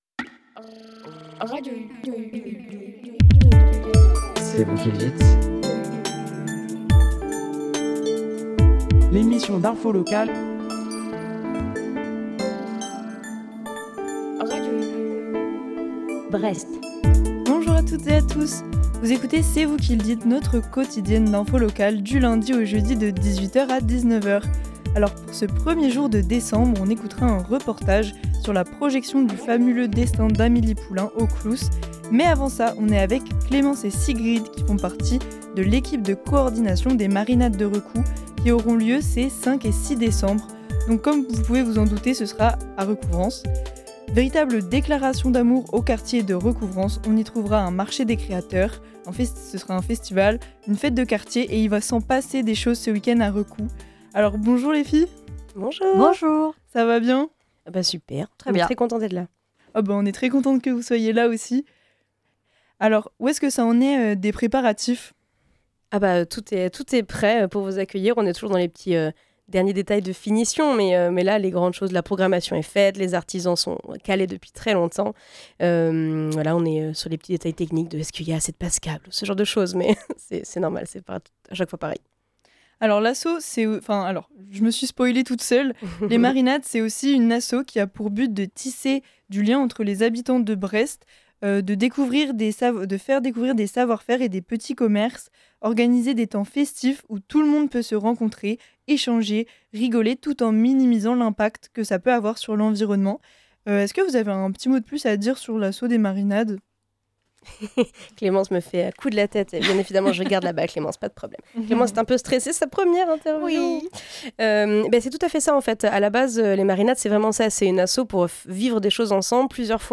CVQLD - MARINADES DE RECOU / REPORTAGE AU CLOUS